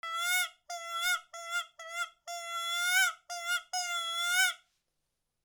Крик подраненного зайца как манок для охоты